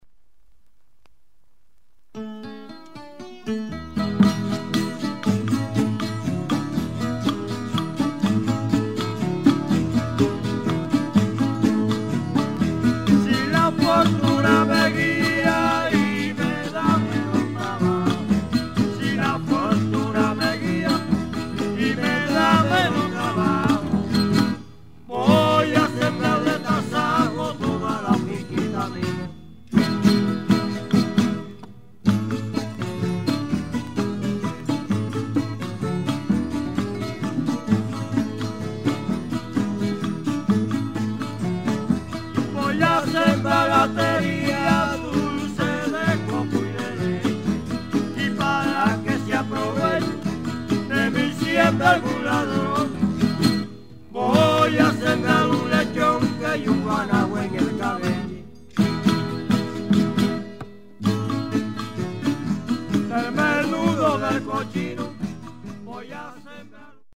Decimas , Tonada , Punto といったキューバの
トラディショナルなフォルクローレとも言うべきジャンルの音楽
Trova (トローバ)に非常に近いテイストです。